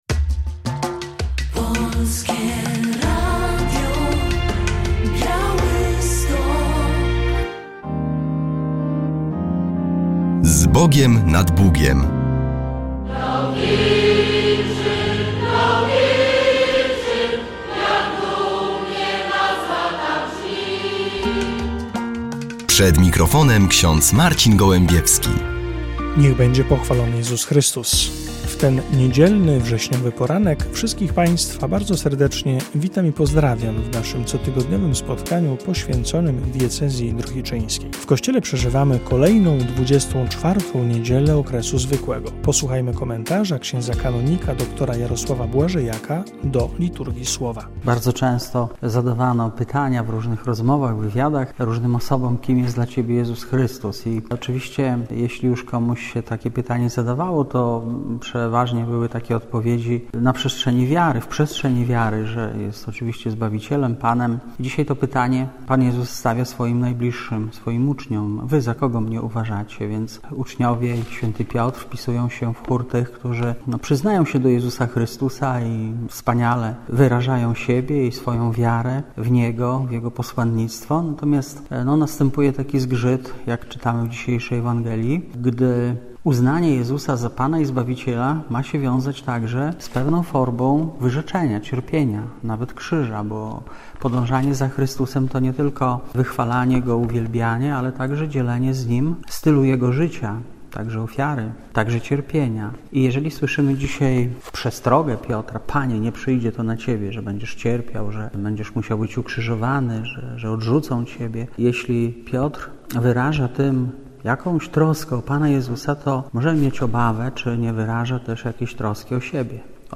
W audycji relacja z Marszu dla Życia i Rodziny w Sokołowie Podlaskim.